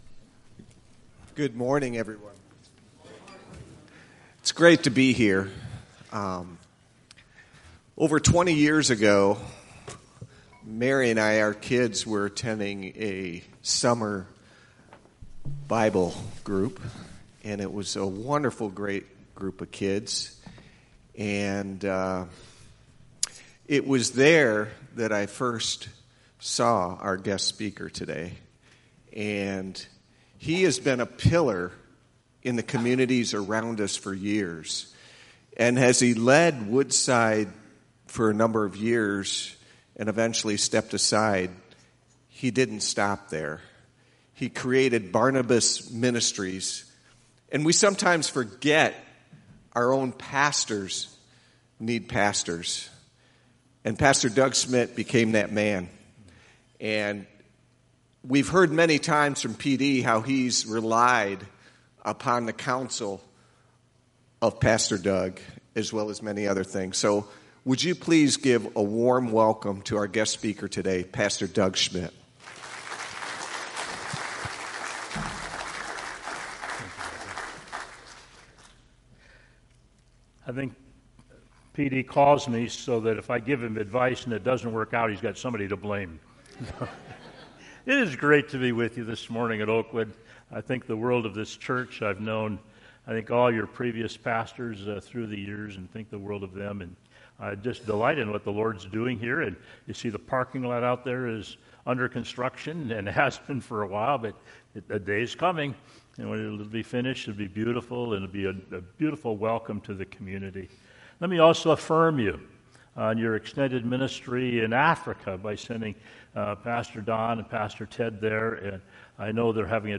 There are no online notes for this sermon.